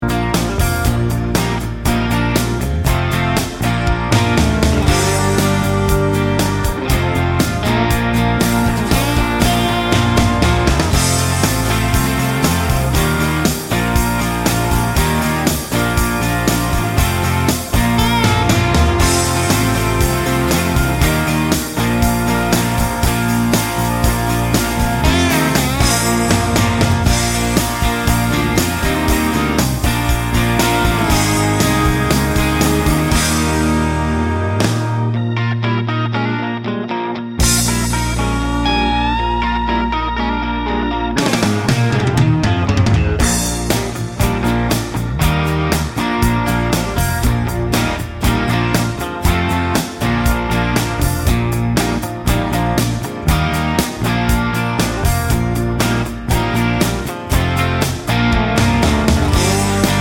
no Backing Vocals Country (Male) 3:23 Buy £1.50